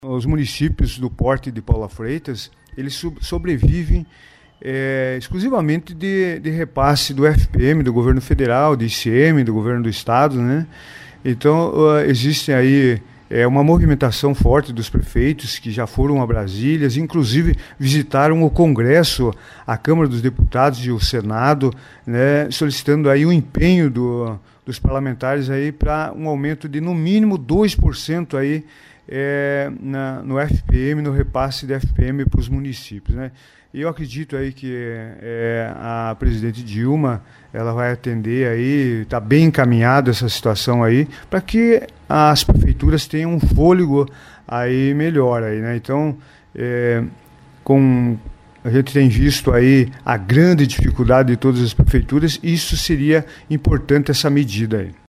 O administrador comentou em entrevista para a Rádio Colmeia, as ações focadas e positivas para a cidade.